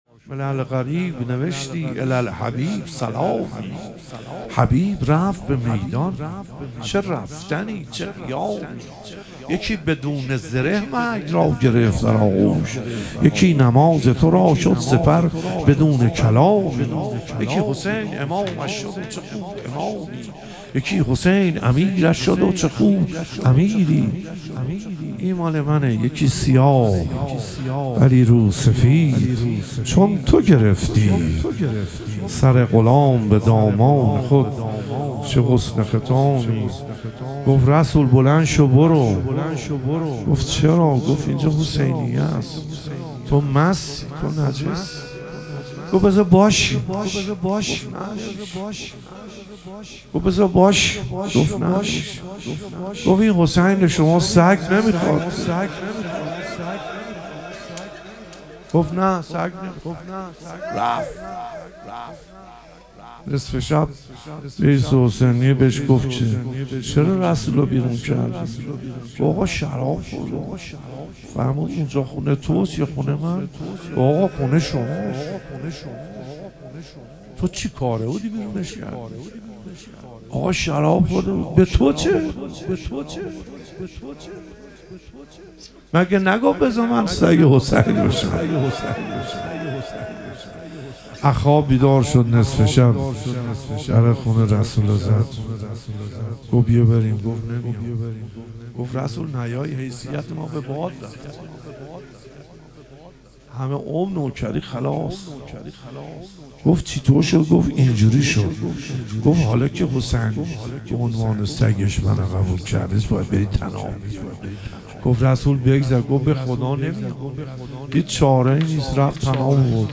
ظهور وجود مقدس حضرت علی اکبر علیه السلام - روضه